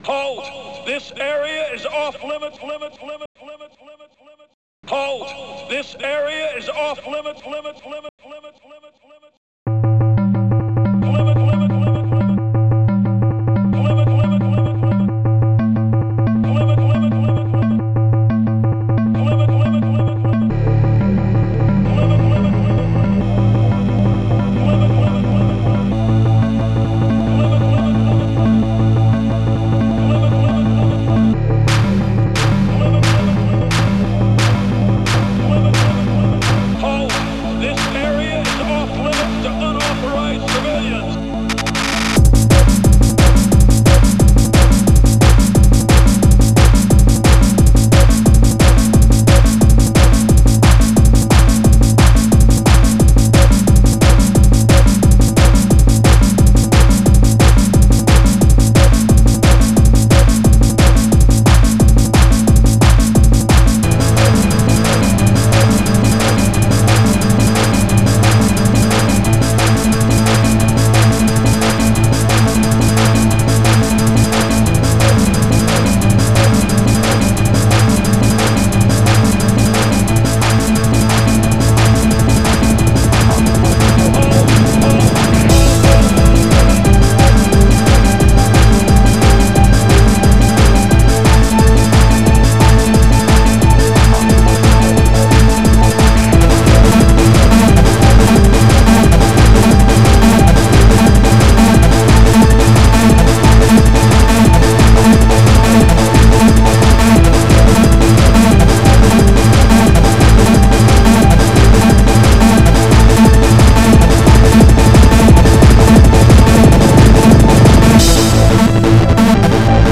Cymbal
Xylophone
Hihat open
Hihat closed
Clap
Darkloop
Bassdrum
Piano
HCbass
Snare